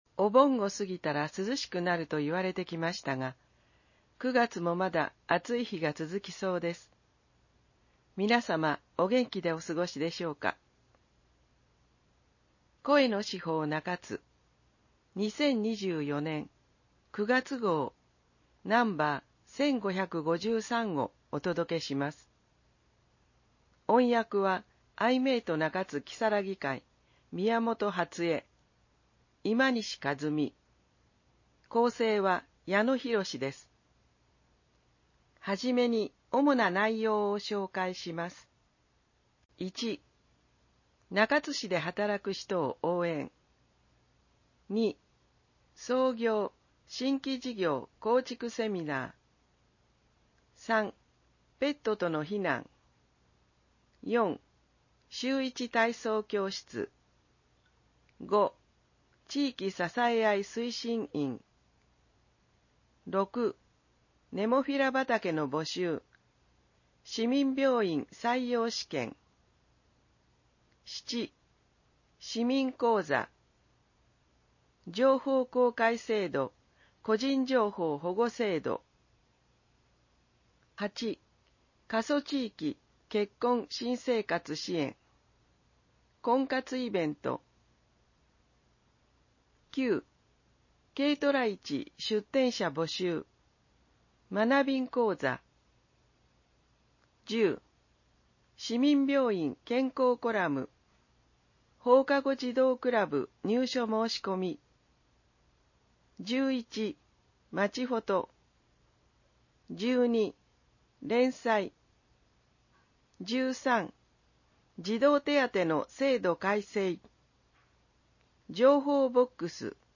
市報の内容を音声で聞くことができます。 アイメイト中津きさらぎ会がボランティアで製作しています。